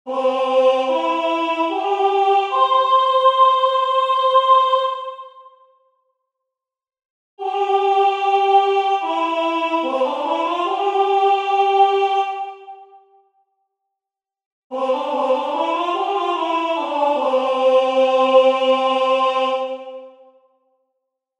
- faremos o mesmo pero con outras secuencias melódicas como:
Distintas secuencias melódicas
vocalizacion.mp3